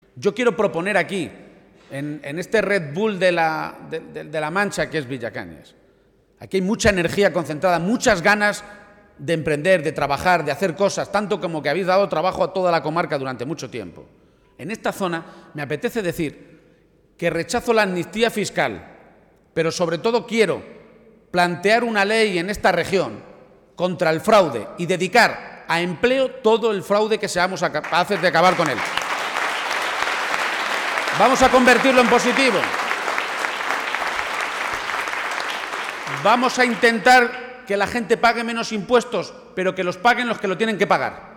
García-Page avanzaba esta medida esta tarde, en un acto público celebrado ante más de 300 personas en Villacañas, en La Mancha toledana, en el que volvía a insistir en que, además, los altos cargos del nuevo Gobierno regional estarán obligados a pagar sus impuestos en Castilla-La Mancha.